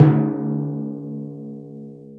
TIMP 2.wav